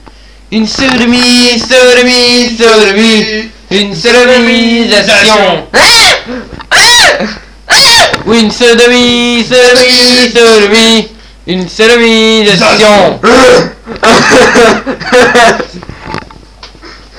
Chanson courte sur le theme de la sodomie
chanson_courte_sodomie.wav